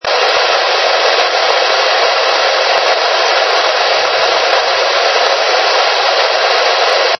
Ils ont été effectués à l'aide d'un camescope placé près du haut-parleur d'un MVT-7100.
Enregistrement 4 : AM. On entend à peine les tops écrasant le souffle du récepteur. Ce mode n'est pas le meilleur.